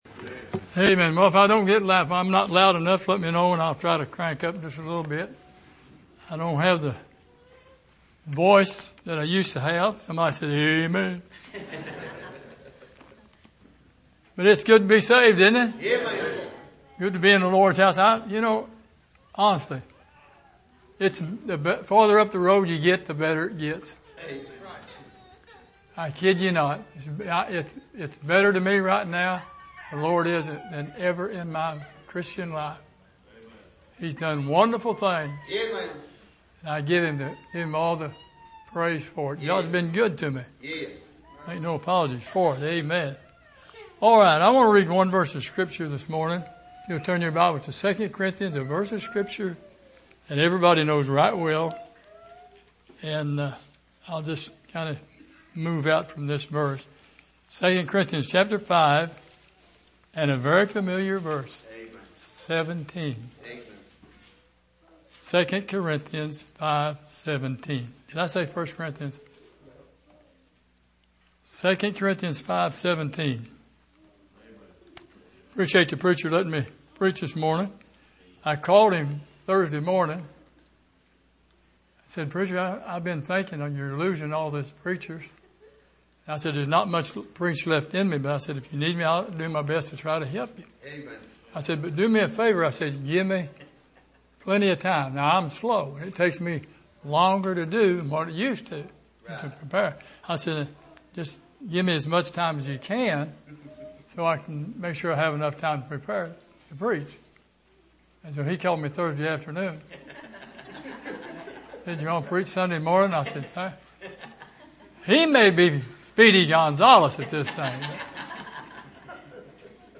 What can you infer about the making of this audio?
Here is an archive of messages preached at the Island Ford Baptist Church.